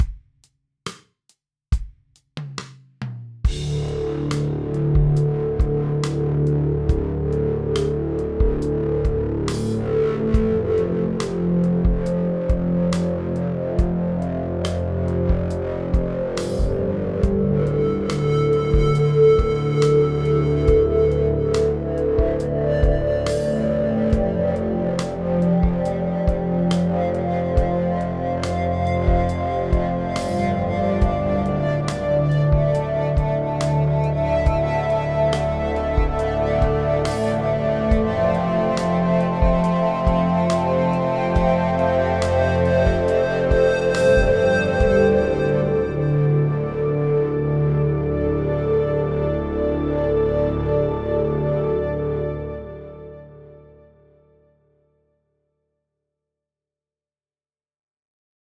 Für einen in gerade mal 2 Minuten von Null aufgebauten Patch mit genau 3 gelayerten, verzerrten Einzelnoten-Es (die ich alle direkt aus dem Looper ins Logic aufgenommen habe) finde ich das eigentlich ganz lustig.
Ist nur der CR8 mit etwas Logic Stereo Delay drauf. Schick finde ich die Stellen, wo es vermeintlich in so'ne Art Feedback umkippt, welches es eigentlich gar nicht gibt.